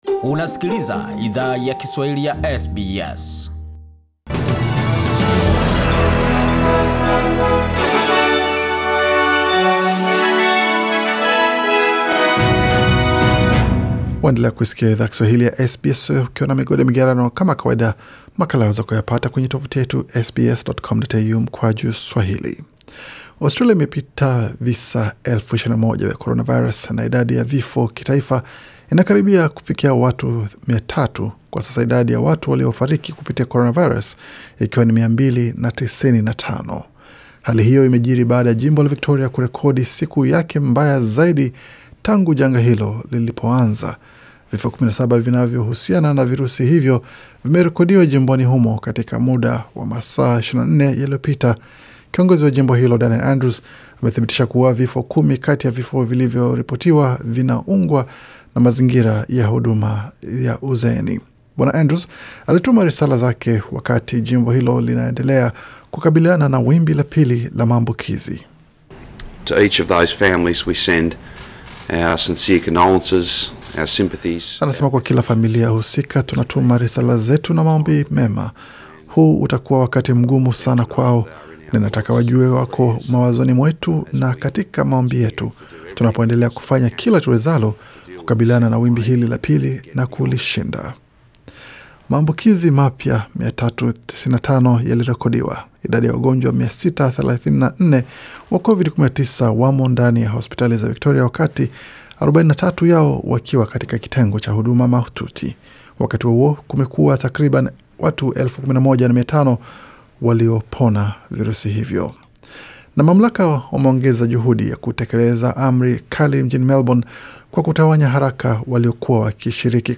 Taarifa ya habari 9 Agosti 2020